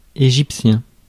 Ääntäminen
IPA: /e.ʒip.sjɛ̃/